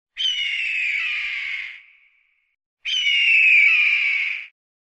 Звуки крика орла
На этой странице собраны звуки криков орлов – от пронзительных охотничьих кличей до переклички в полете.